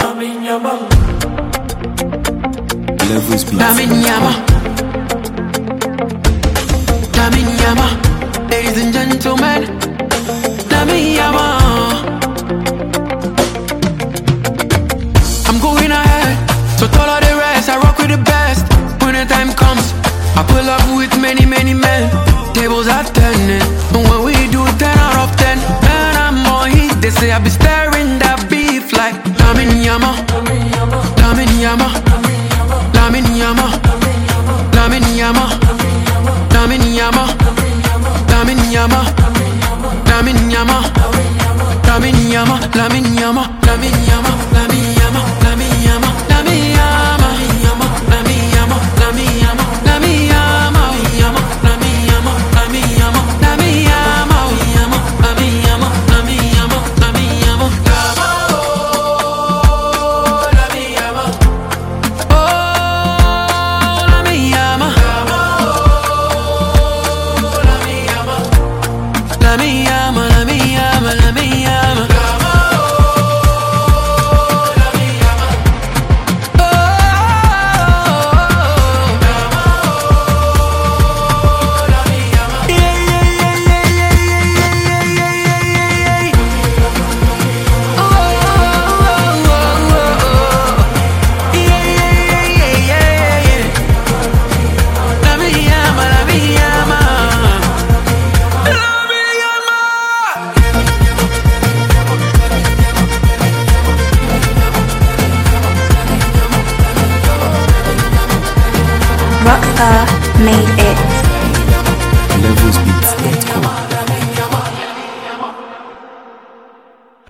Ghana Music 2025 1:57